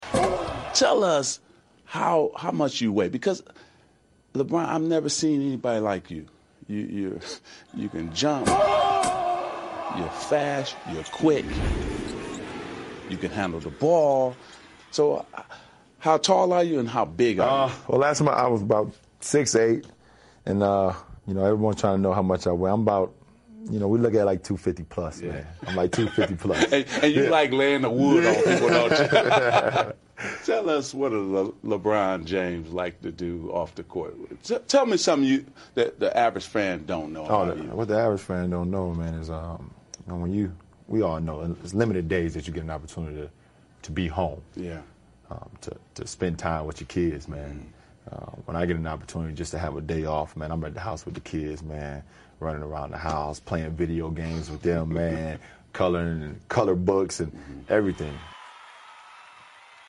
篮球英文堂 第141期:魔术师采访詹皇 科比是你的劲敌吗?(3) 听力文件下载—在线英语听力室